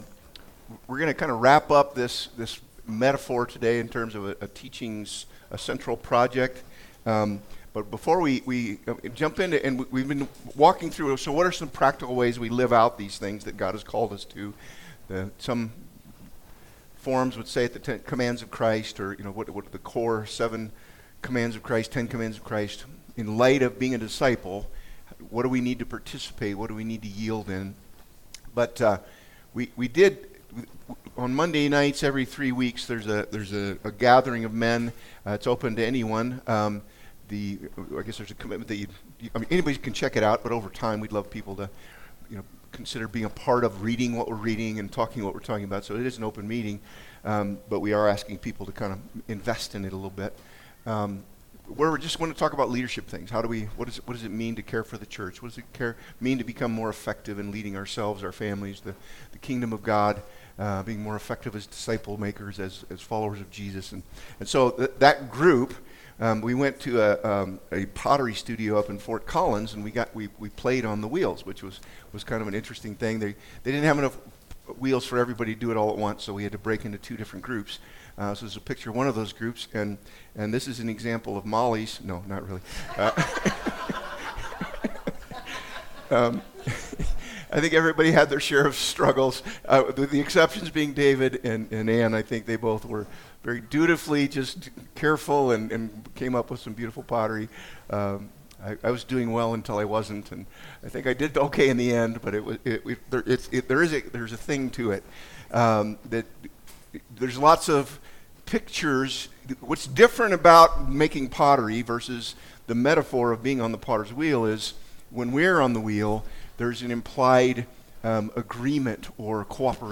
Life In the Potter's Hands Service Type: Sunday « The Call of Christ Identity in Christ- Saint or Sinner?